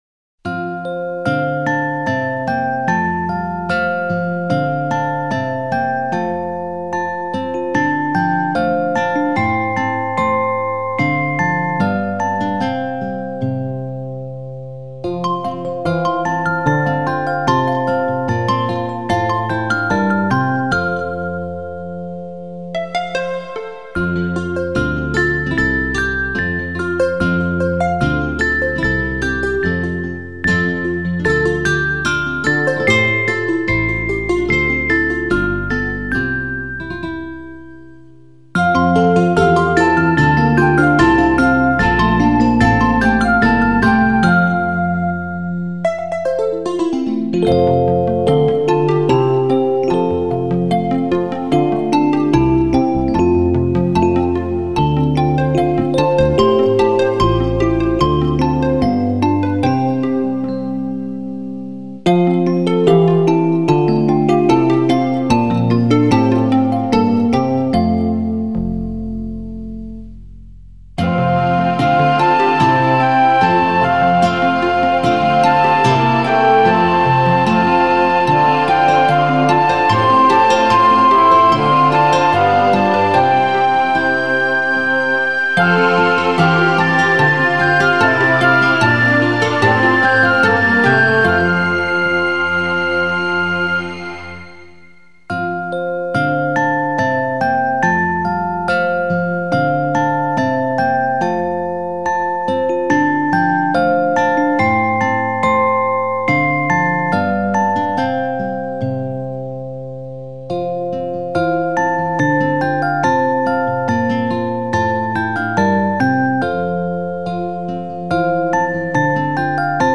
键盘
精致、细腻，满足宝宝娇嫩敏感的小耳朵。
本辑中以法国的传统音乐为主，明亮轻快的节奏将唤醒熟睡中的孩子，轻启他 惺忪的双眼。